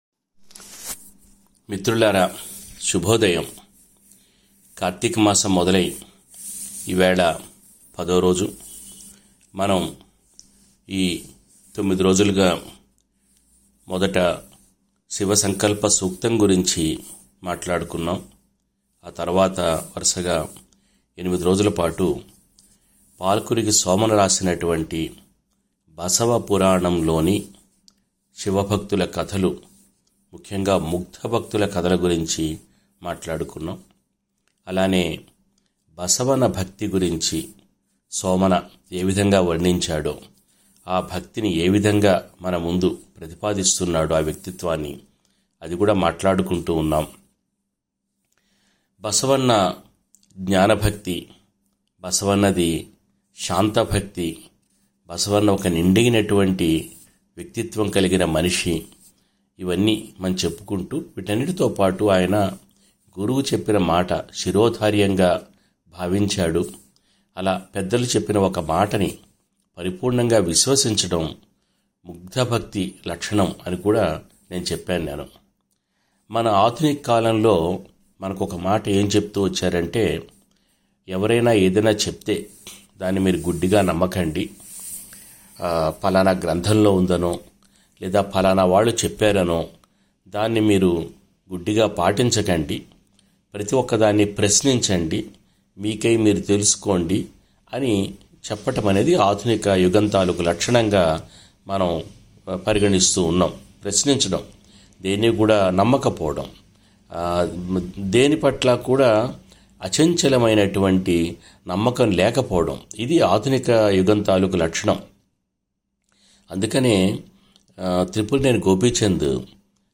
పాల్కురికి సోమన బసవపురాణం మీద చేస్తూ వచ్చిన ప్రసంగాల్లో ఇది తొమ్మిదో ప్రసంగం. మధ్యయుగాల భక్తి సాహిత్యమంతటిలోనూ ప్రధానంగా కనవచ్చే లక్షణం ఆ కవులకి మనిషి ముఖ్యం తప్ప అతడి జాతీ, కులం ముఖ్యం కాకపోవడం.